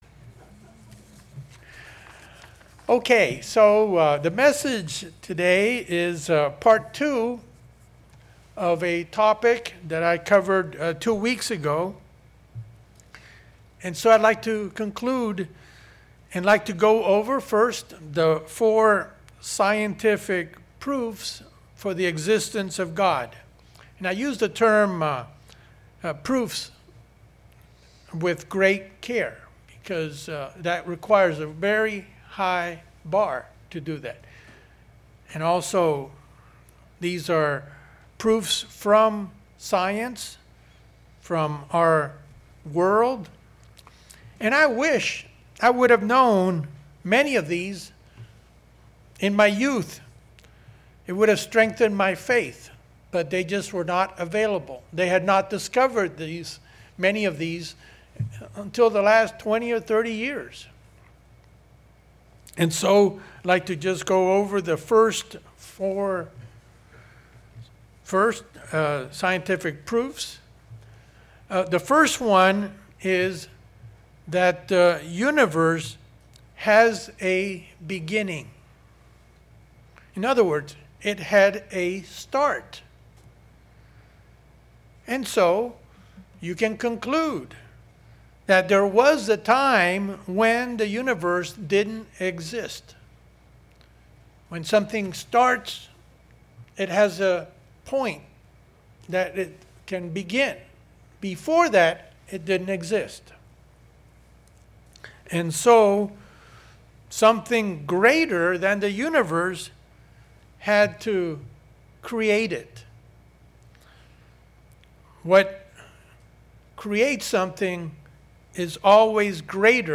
With this sermon, we cover Part 2 of this interesting subject.